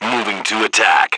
1 channel
H_soldier1_21.wav